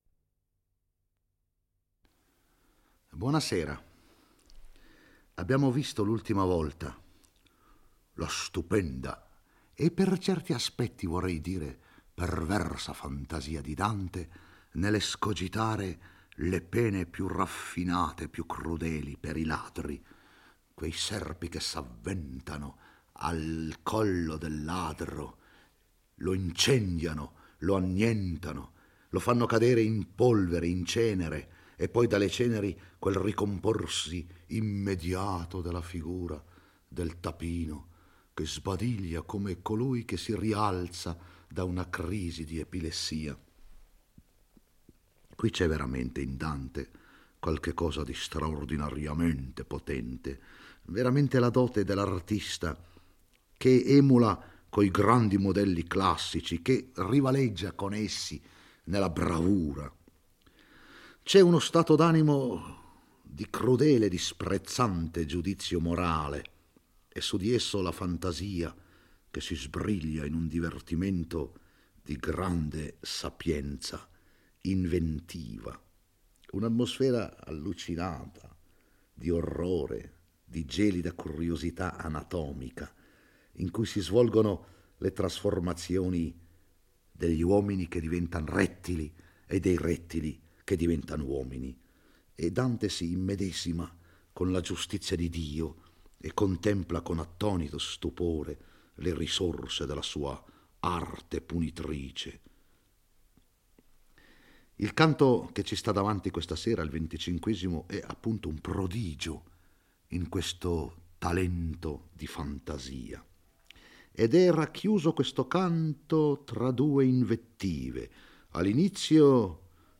legge e commenta il XXV canto dell'Inferno.